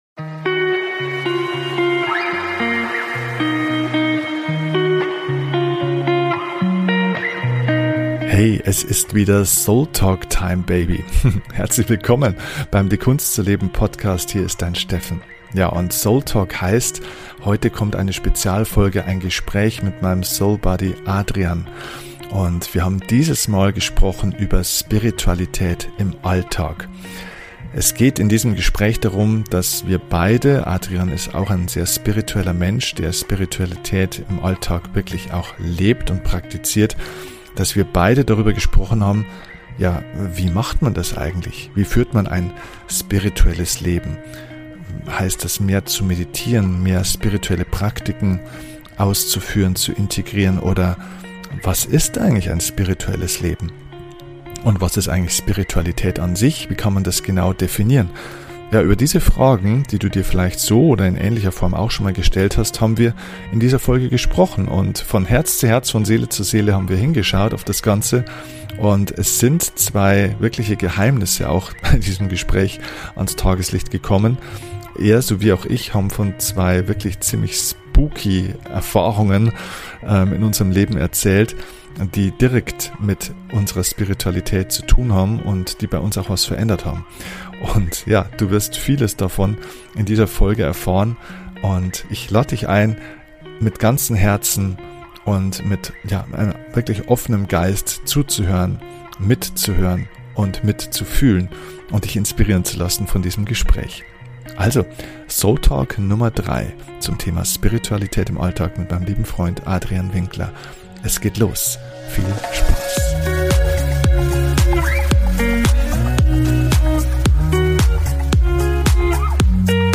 Bei diesem Format gibt es kein Skript, keine Vorbereitung und keine Richtung. Heute reden wir über das Thema Spiritualität. Wie kann man Spiritualität im Alltag leben?